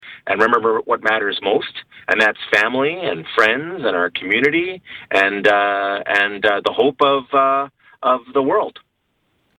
Mayor Mitch Panciuk tells Quinte News, “In a year like this, with COVID-19, it’s time to celebrate.”